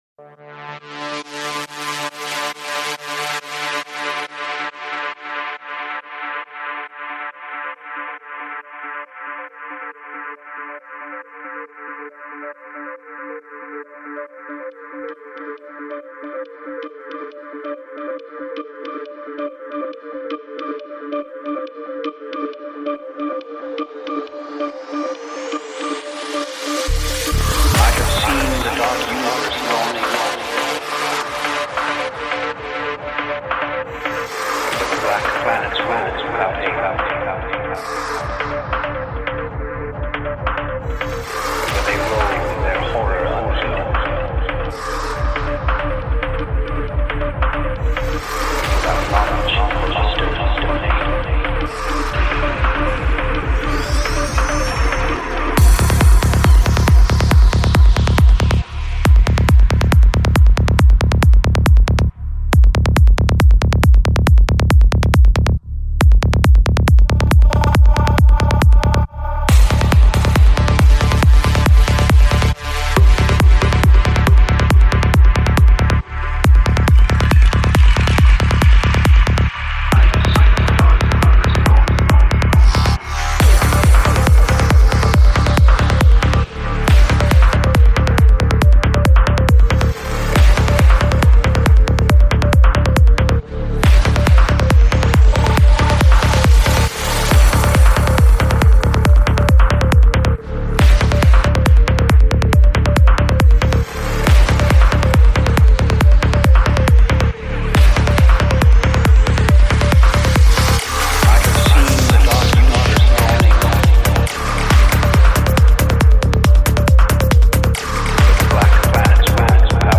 Жанр: Psy Trance